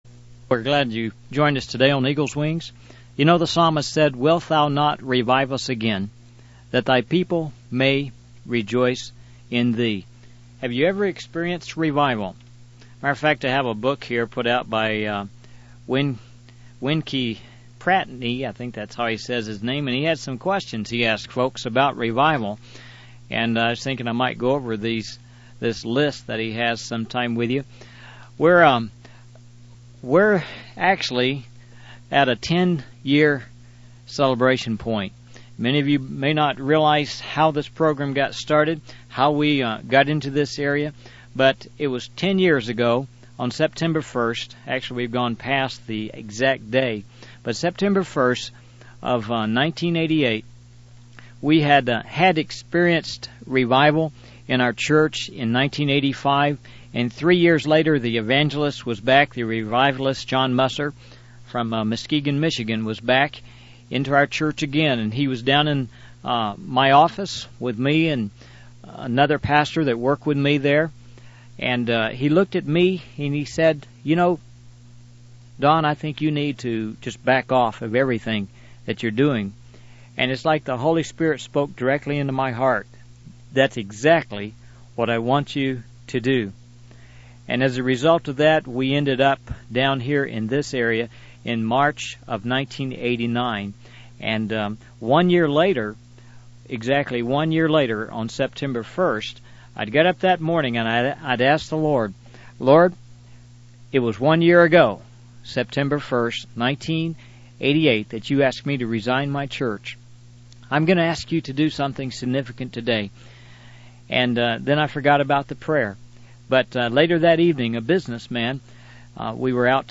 The video is a testimony of a revival that took place in a small Baptist Church in northwestern Nebraska. The preacher emphasizes the need for revival and warns that without it, the church is on the path to ruin. The speaker shares how God answered their prayers for revival and how it transformed their church and community.